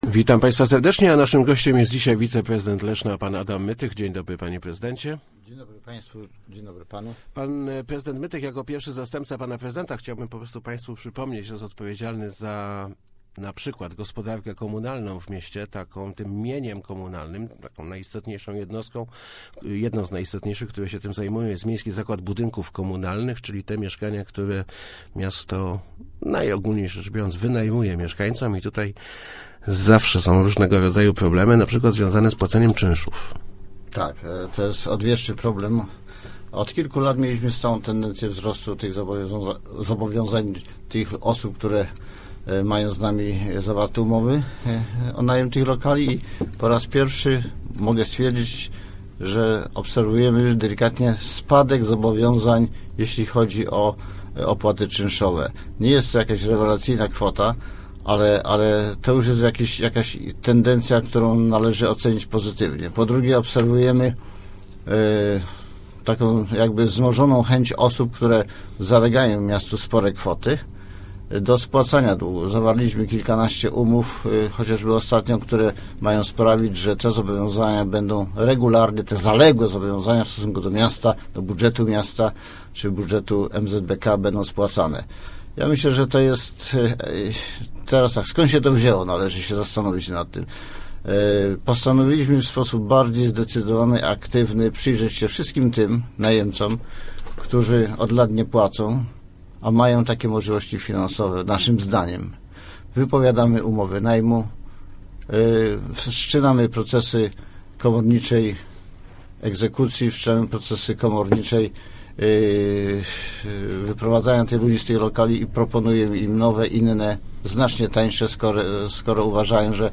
Zmniejsza si� zad�u�enie lokatorów mieszka� komunalnych – powiedzia� w Kwadransie Samorz�dowym wiceprezydent Adam Mytych. W ci�gu ostatnich miesi�cy uda�o si� odzyska� oko�o 200 tysi�cy z�otych zaleg�o�ci.